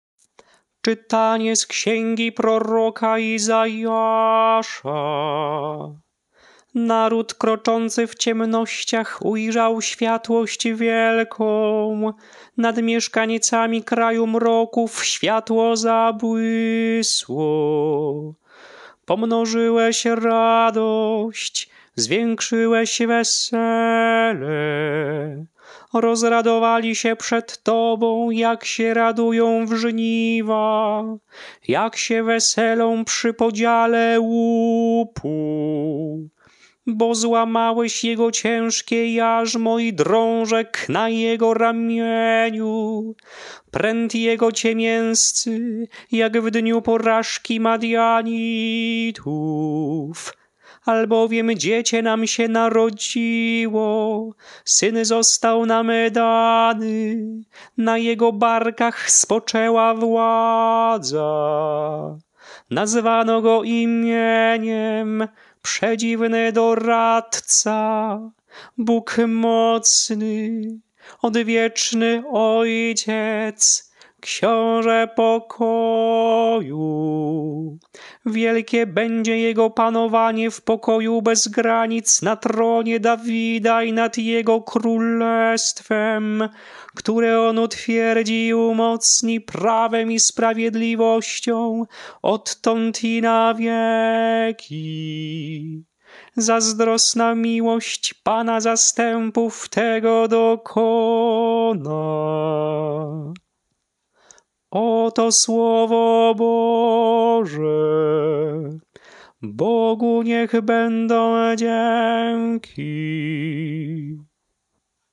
Śpiewane lekcje mszalne - Uroczystość Narodzenia Pańskiego - Z pasji do liturgii
Melodie lekcji mszalnych przed Ewangelią na Uroczystość Narodzenia Pańskiego:
Narodzenie-Panskie-Msza-o-polnocy-ton-proroctwa.mp3